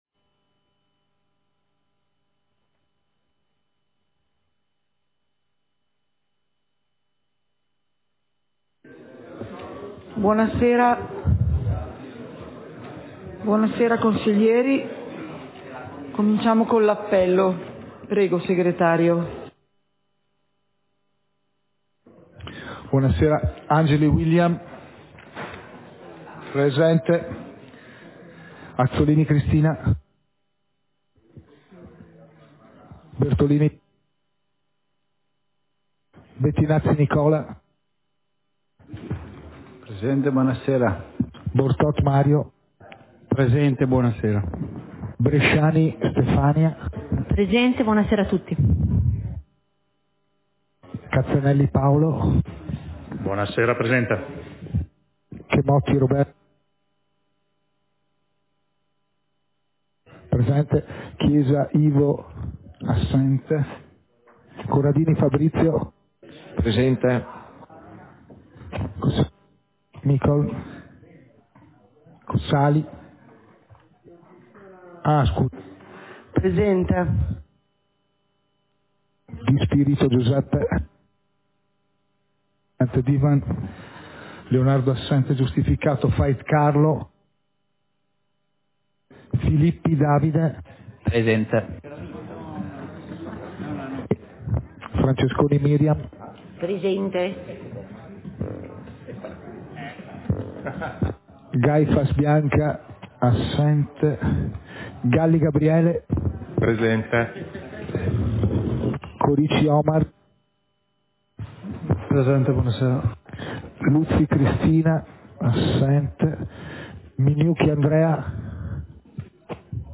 Seduta del consiglio comunale - 15.12.2023